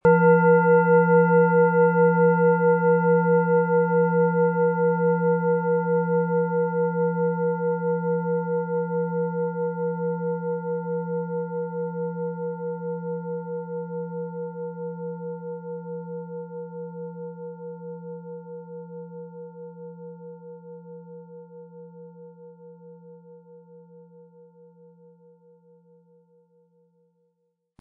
Planetenton 1
Sie sehen eine Planetenklangschale Chiron, die in alter Tradition aus Bronze von Hand getrieben worden ist.
Unter dem Artikel-Bild finden Sie den Original-Klang dieser Schale im Audio-Player - Jetzt reinhören.
MaterialBronze